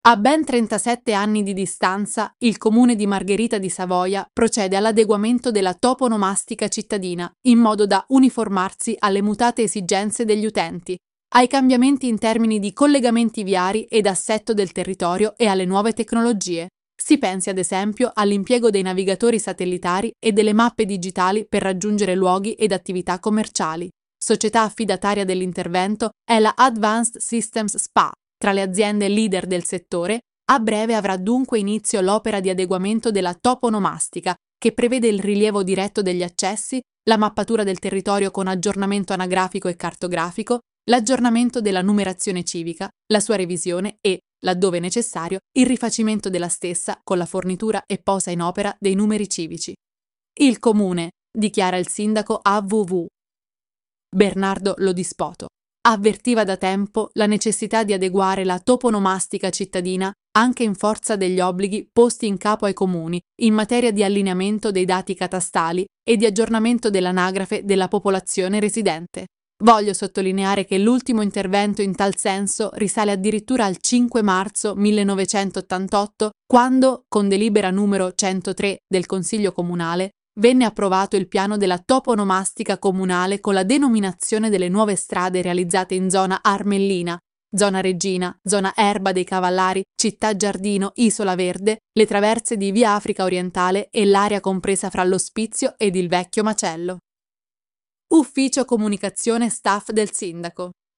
Audiolettura)